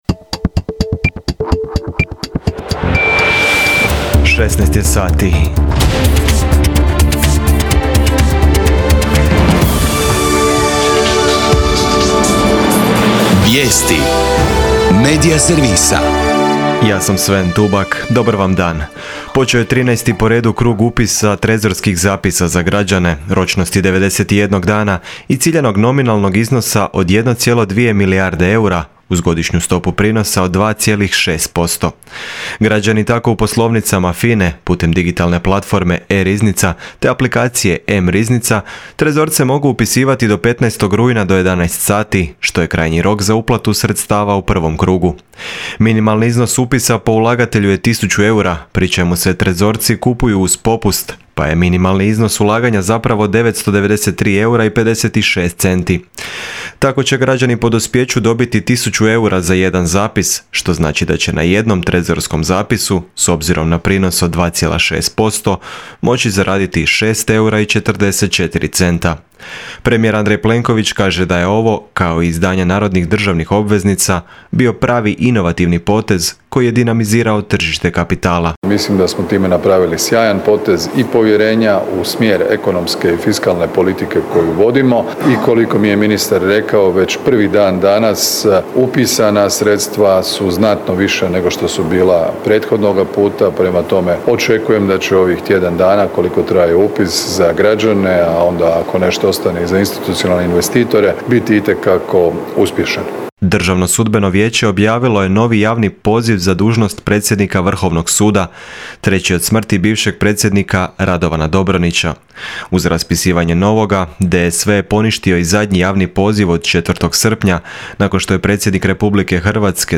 VIJESTI U 16